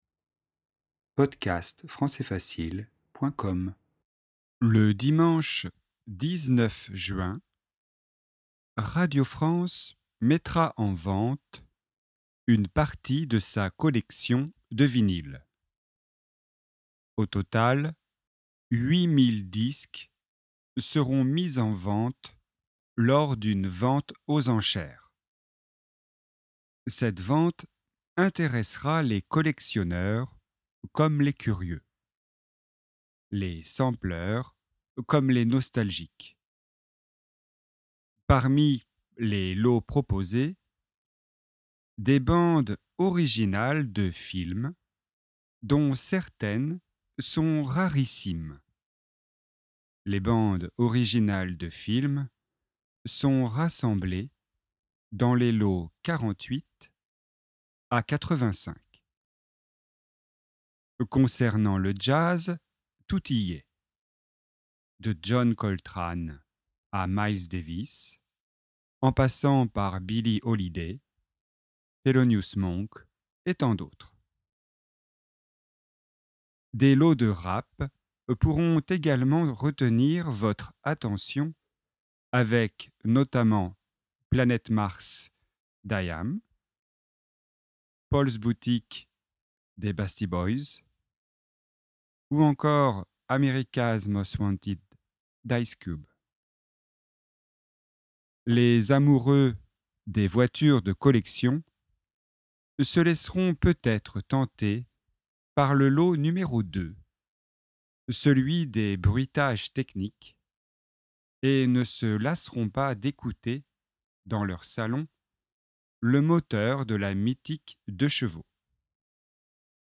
Texte lecture lente